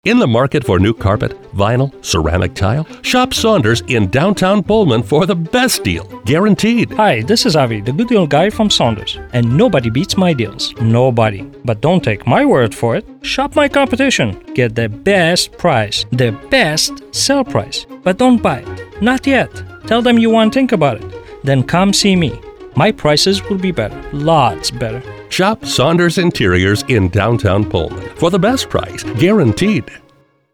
Accentuate the Positives: Client-Voiced Radio Commercials
What about advertisers with pronounced accents?